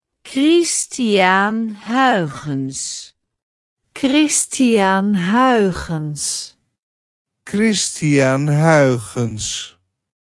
Pronunciation_Christiaan_Huygens.ogg